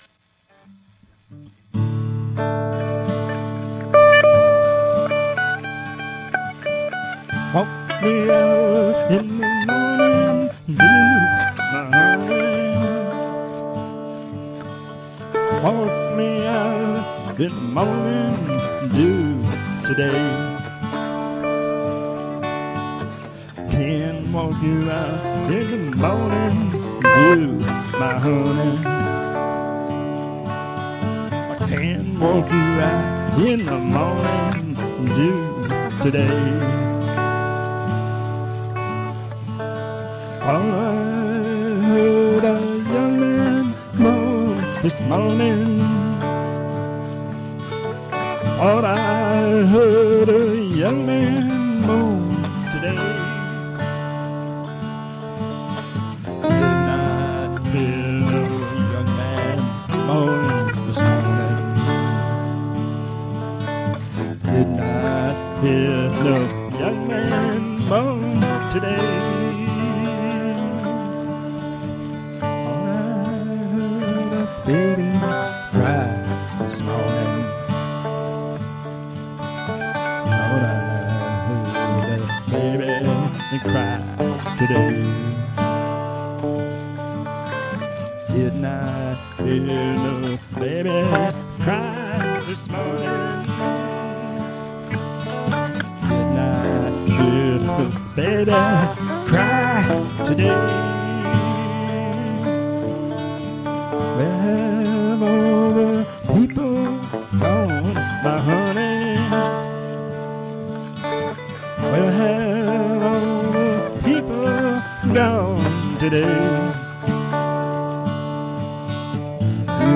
I play guitar (can cover on bass) and keyboards.
Reasonably good on vocals.